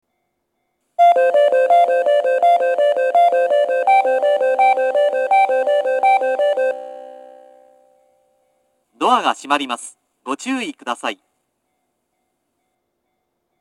接近放送、発車メロディーと中央制御型の放送は別々のスピーカーから流れます。
１番線接近放送